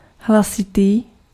Ääntäminen
IPA : /laʊd/ US : IPA : [laʊd]